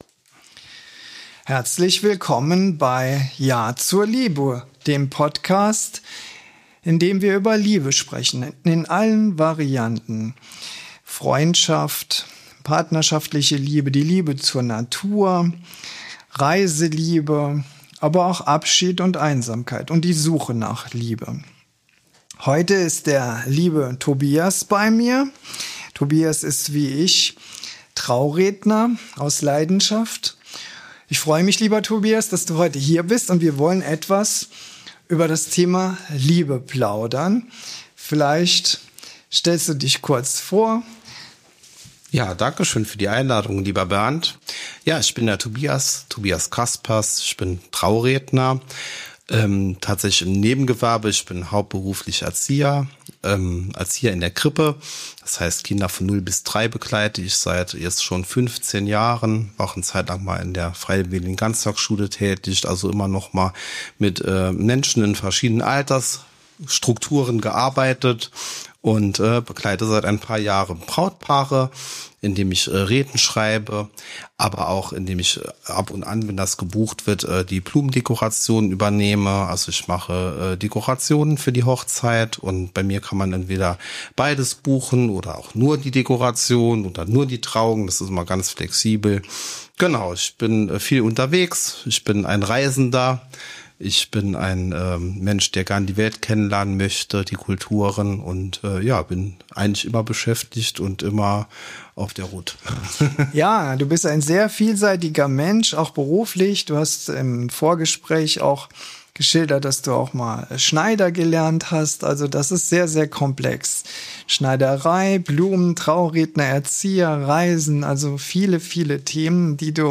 Ehrlich, tief, locker - mit kindlicher Leichtigkeit